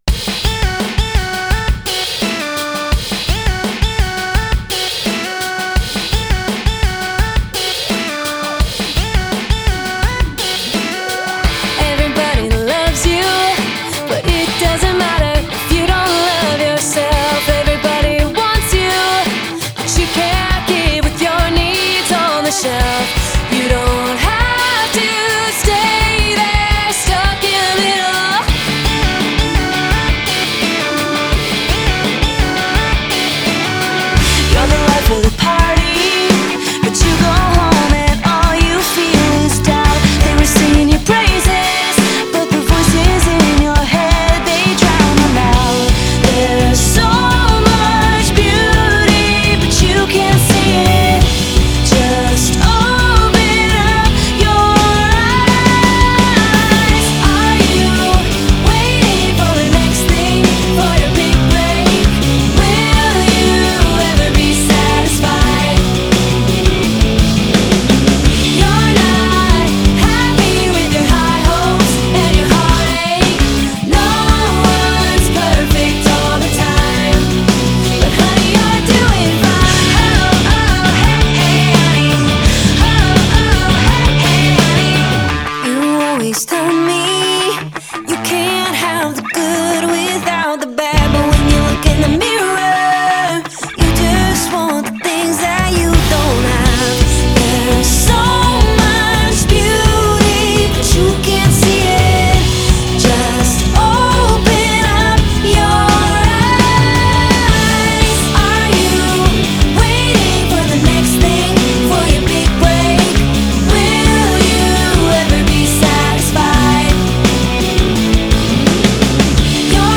Genre: Indie Pop, Alternative